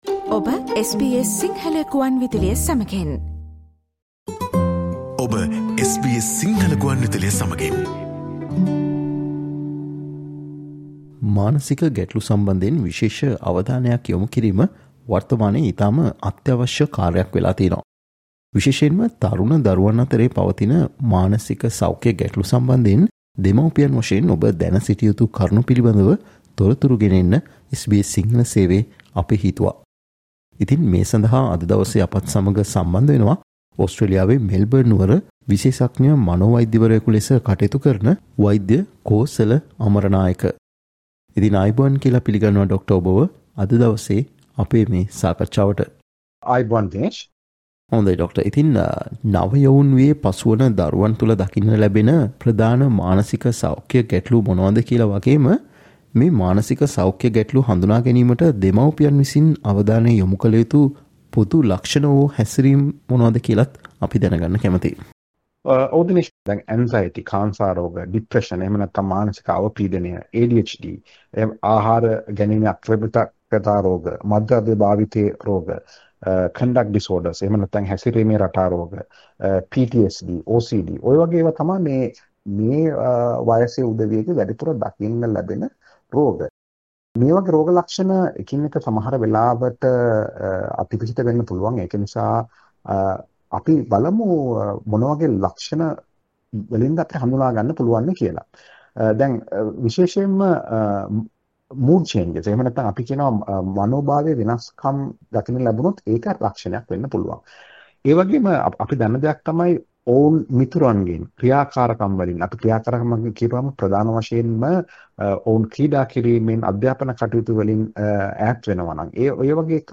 Listen to SBS Sinhala discussion on how parents can spot early signs of mental health issues in young children.